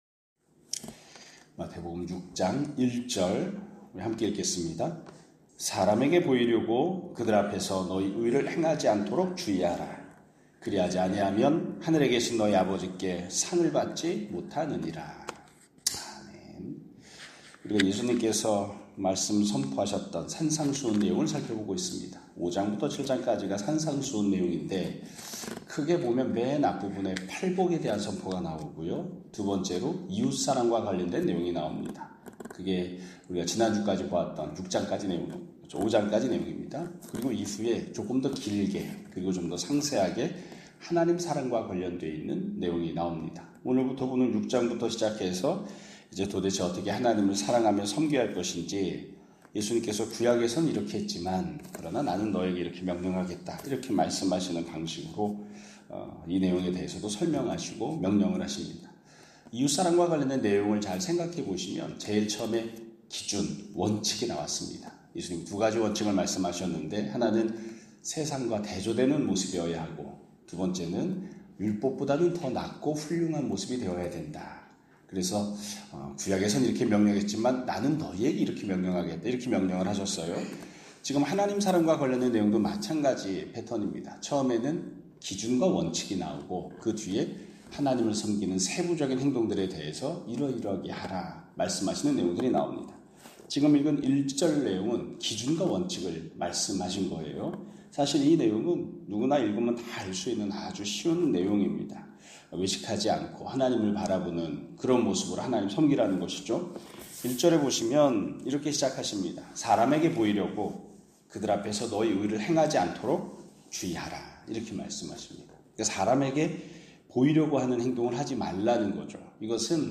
2025년 6월 9일(월 요일) <아침예배> 설교입니다.